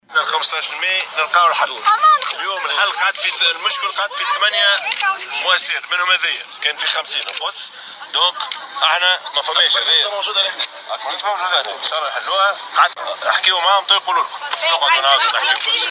قال رئيس الحكومة يوسف الشاهد، في لقاء جمعه بعدد من الطلبة المحتجين أمام محضنة المؤسسات ببرج السدرية، إنه سيتم التوصل إلى حلول تنهي أزمة التعليم العالي، ويتم بموجبها إجراء الامتحانات قبل تاريخ 15 ماي.